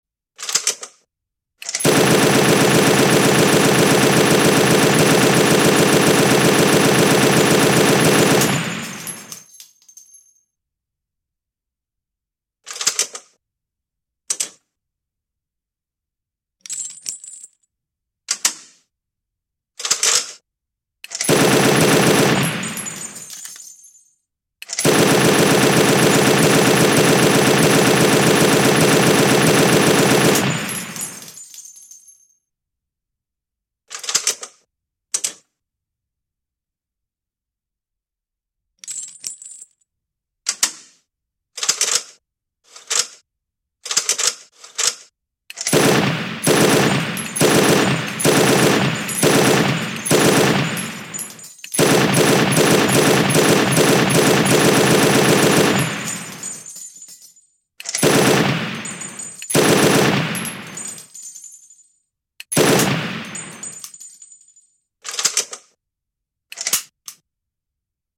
FN MAG 🏴‍☠ Sound Effects Free Download